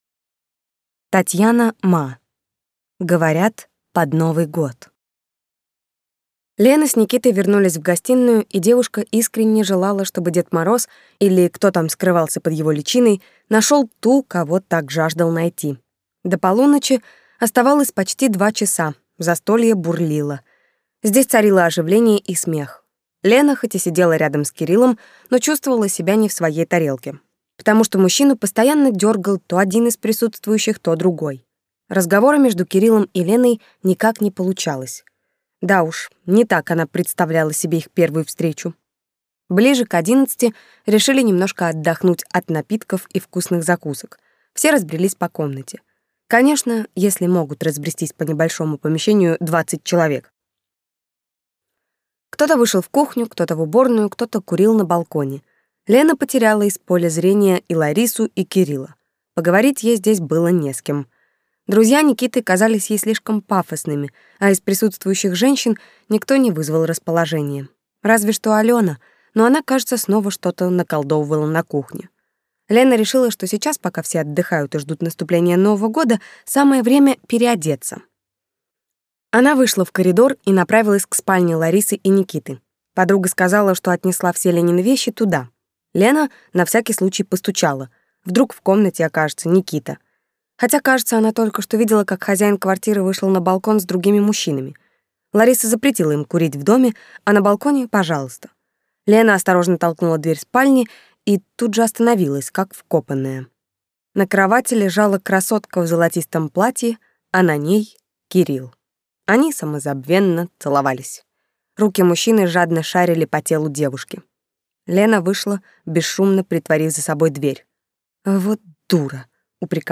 Аудиокнига Говорят, под Новый год…